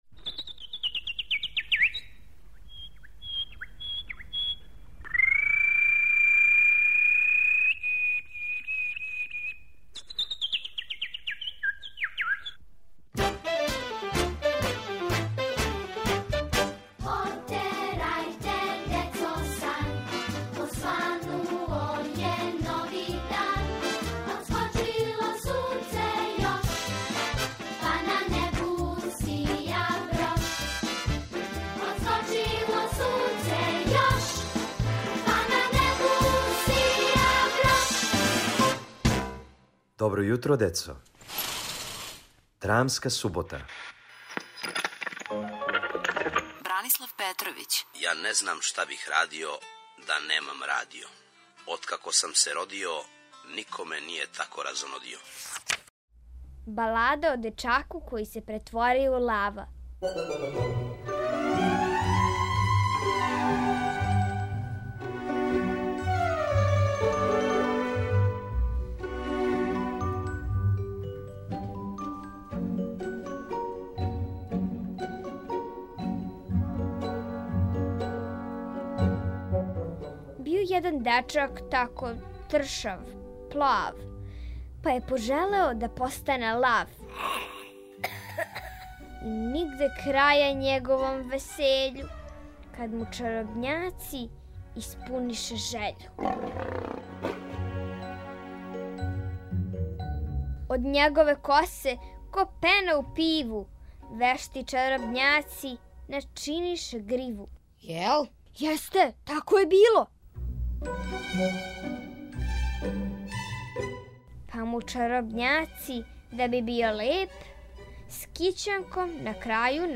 Сазнајте како се један дечак претворио у лава и шта је после било, у драматизованој песми Бране Петровића.